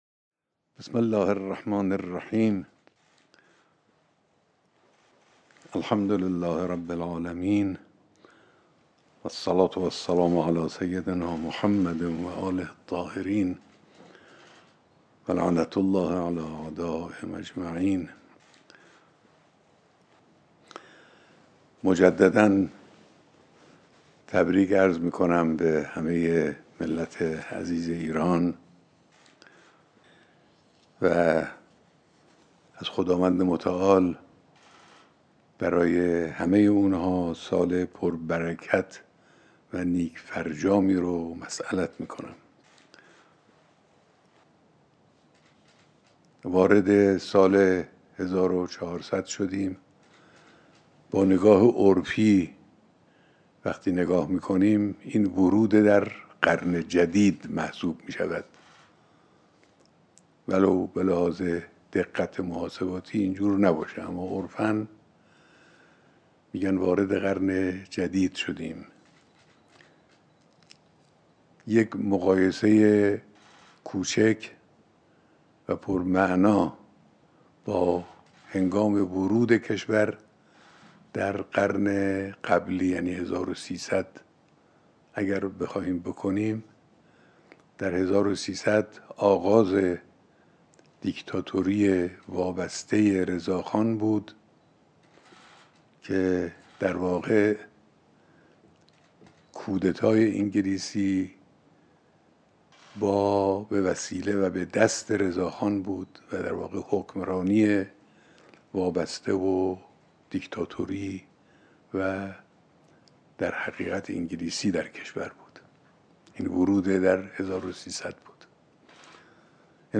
سخنرانی نوروزی رهبر انقلاب اسلامی خطاب به ملت ایران